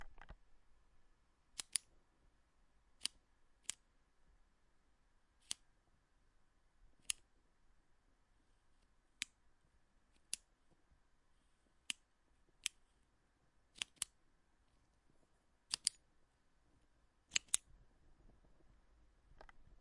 点击笔
Tag: 单击 桌面 使用 钢笔 点击 点击 声音